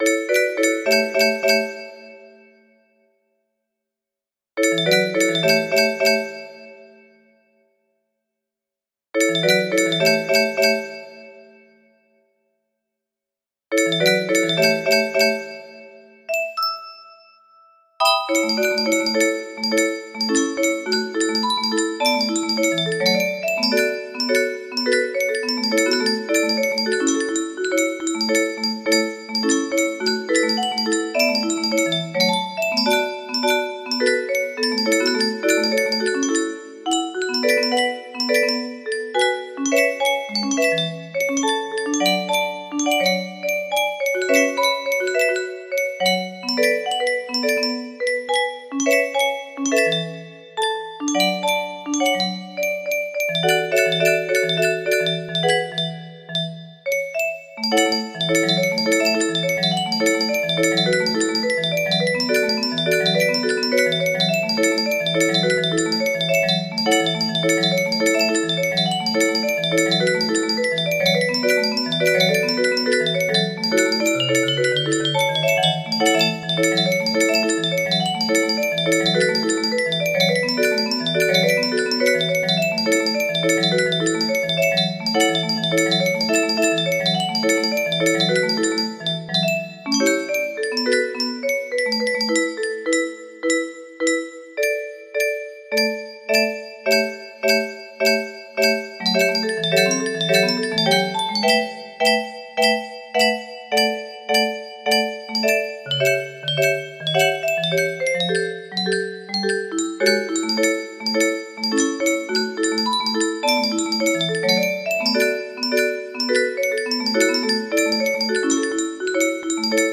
Full range 60
Now its With No Reds, Proper Tempo.